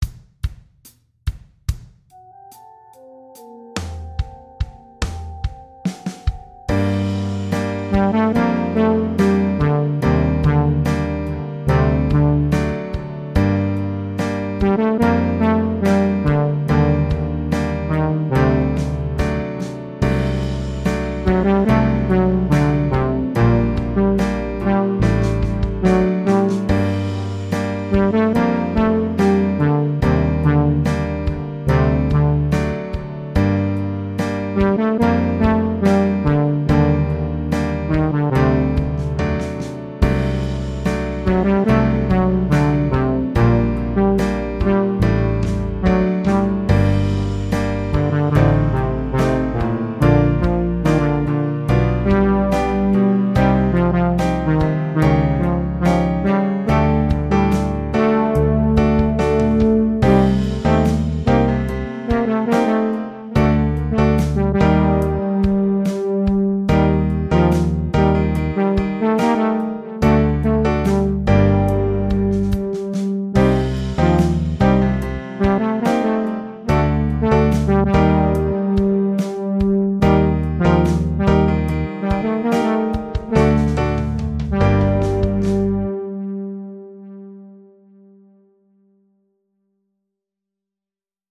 ..meine Song-Skizze ist auch so ein iPad-Ding..ich war im Urlaub..es gab immerhin ein E-Piano, um zu komponieren..